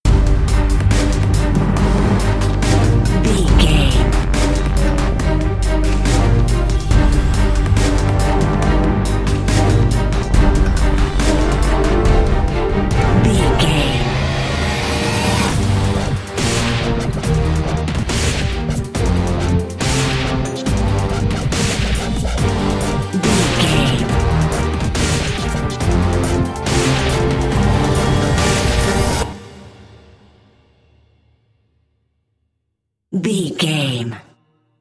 Epic / Action
Aeolian/Minor
drum machine
synthesiser
brass
driving drum beat
epic